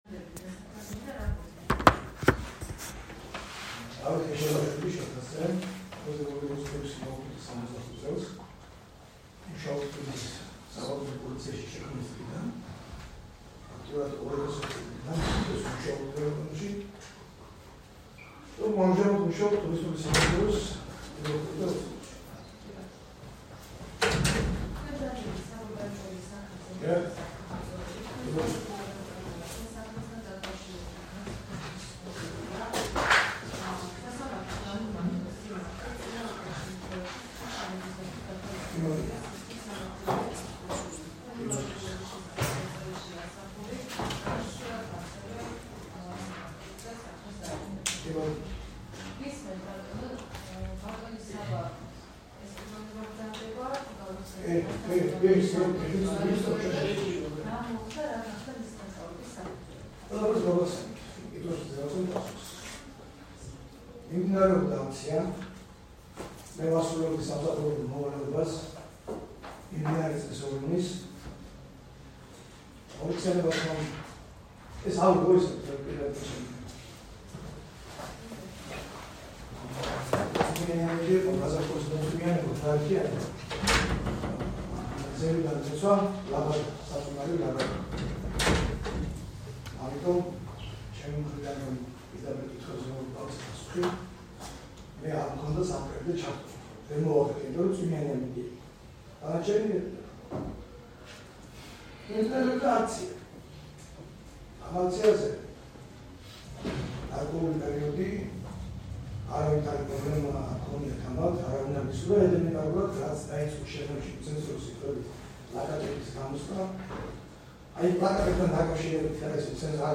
- რეპორტაჟი სასამართლოდან